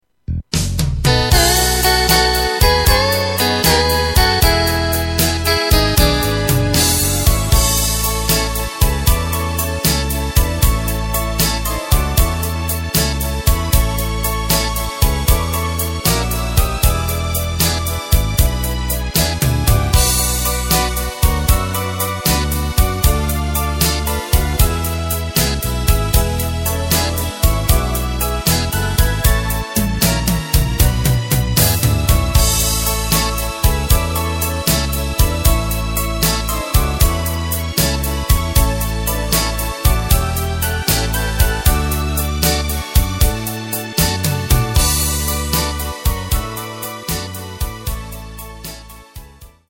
Takt:          4/4
Tempo:         116.00
Tonart:            A
Schlager aus dem Jahr 2005!
Playback mp3 Demo